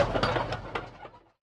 tank-tracks-stop-2.ogg